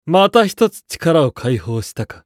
厨二病ボイス～戦闘ボイス～